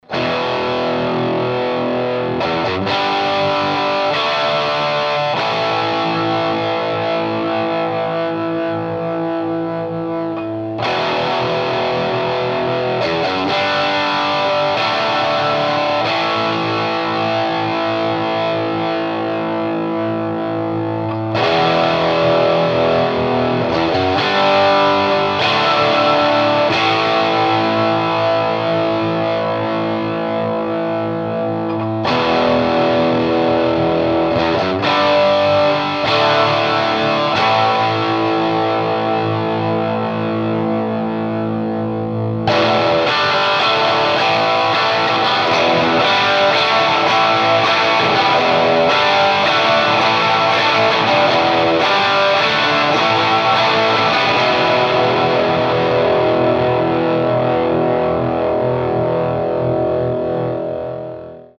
TONE SAMPLES - All clips plugged straight into amp unless noted.
1/2 watt, (sorry, I am not quite as tight as the brothers Young).
All samples done on the original prototype using the original output